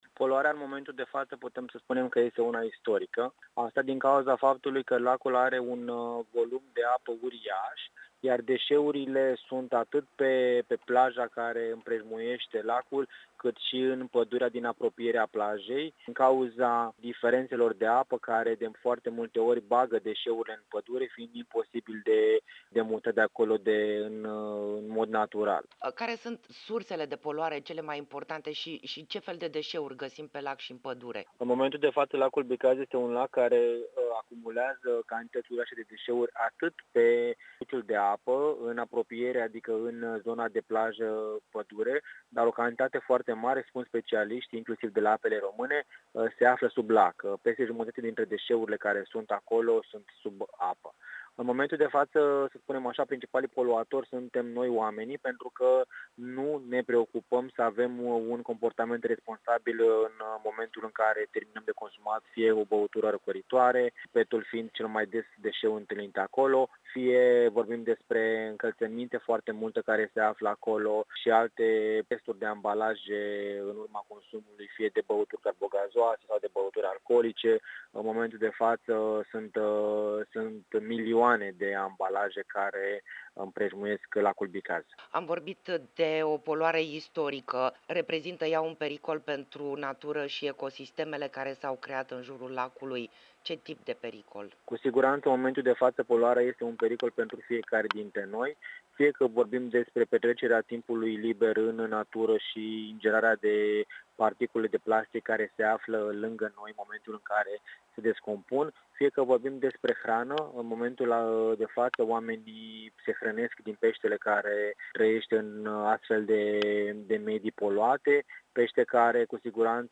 Interviu-poluare-pe-Bicaz.mp3